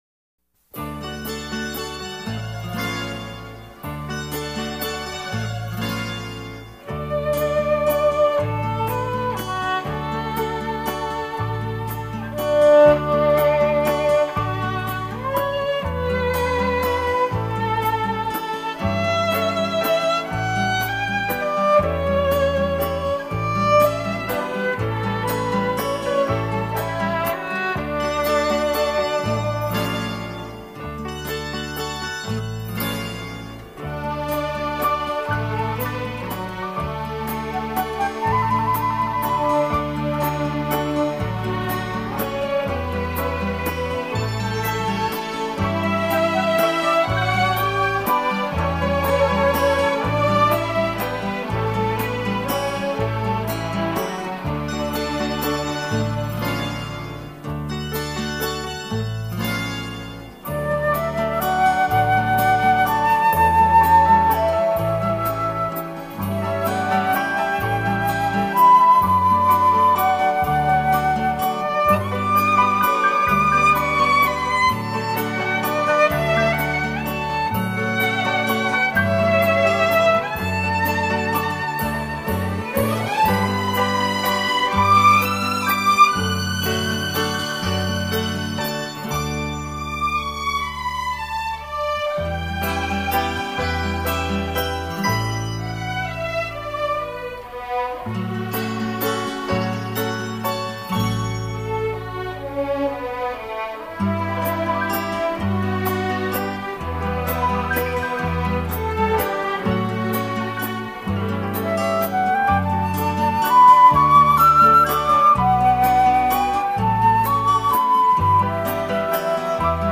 幽秘的旋律轻轻飘落床前，如梦如幻的音符掠过心湖，宁静夜晚音乐是梦的天堂。